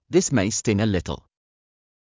ﾃﾞｨｽ ﾒｲ ｽﾃｨﾝｸﾞ ｱ ﾘﾄｩ